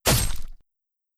Melee Weapon Attack 12.wav